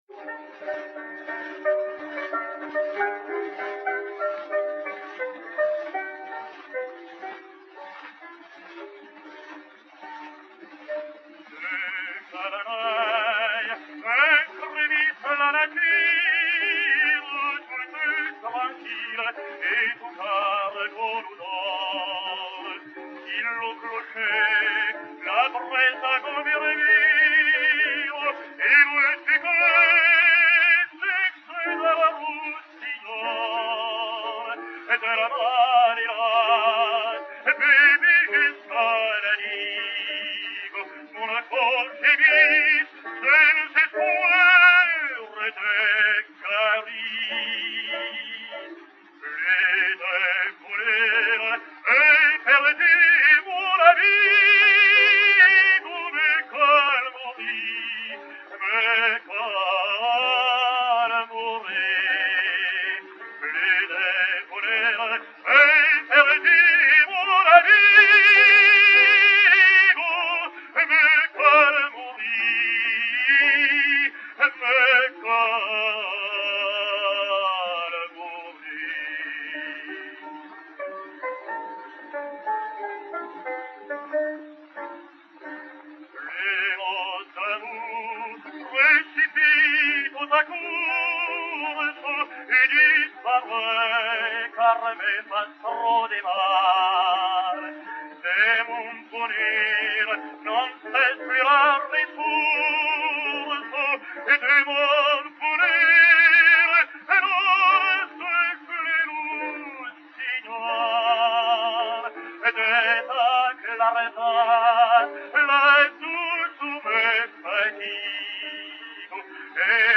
Francesco Tamagno (Otello) et Piano
Disque Pour Gramophone 52068, enr. à Milan en 1904